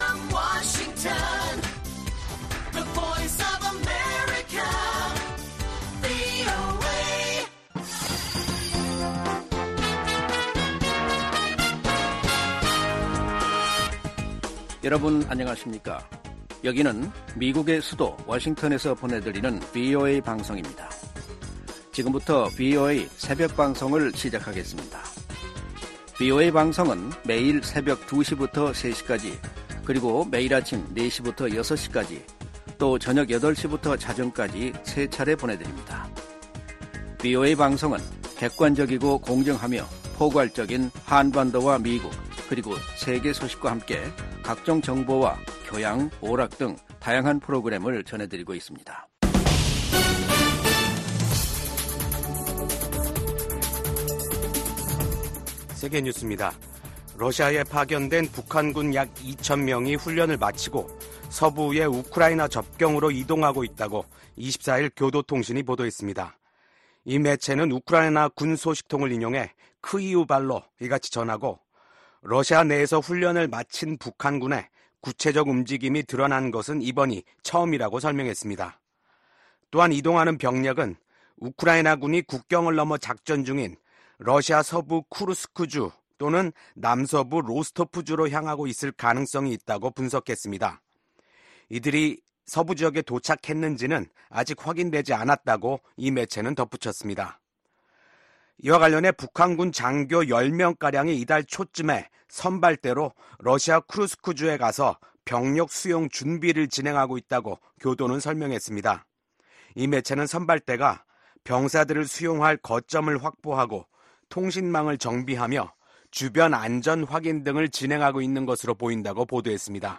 VOA 한국어 '출발 뉴스 쇼', 2024년 10월 25일 방송입니다. 미국 백악관이 북한군의 러시아 파병을 공식 확인했습니다. 최소 3천명이 러시아 동부 전선에 파병됐으며 훈련 뒤엔 우크라이나와의 전투에 배치될 가능성이 있다고 밝혔습니다.